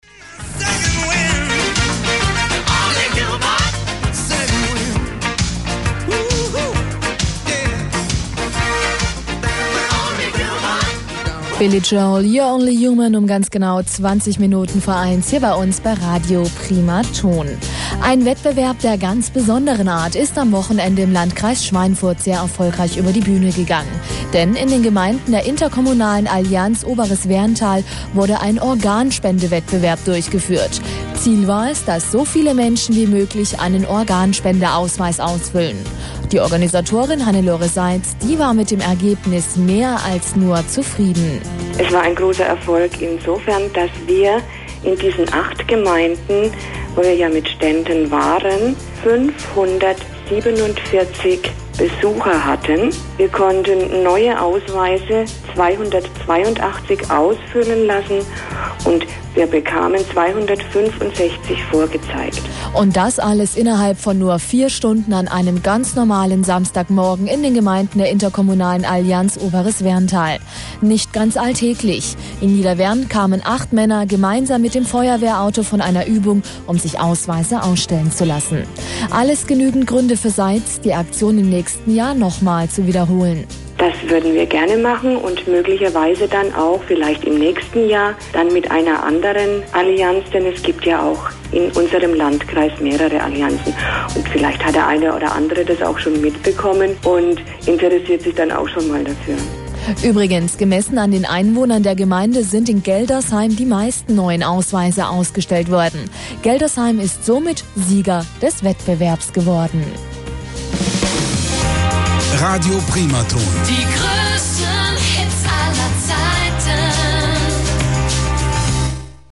Radio-Beitrag zum Organspende-Wettbewerb - Primaton Schweinfurt